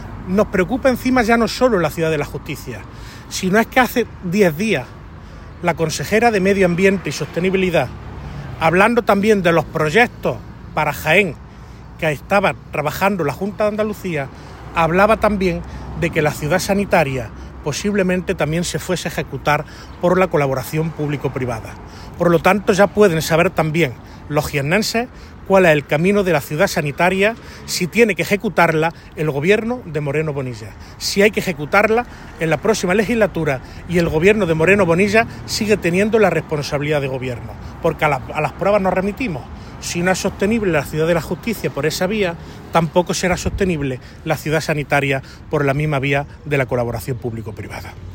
El parlamentario hizo estas declaraciones junto al solar de la Ciudad de la Justicia, donde manifestó que al consejero de la Junta “se le ha acabado el terreno de juego y las excusas” con este proyecto, que a día de hoy “sigue sin una perspectiva clara después de 8 años de Moreno Bonilla”.